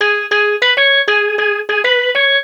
Swinging 60s 6 Organ Lk-G#.wav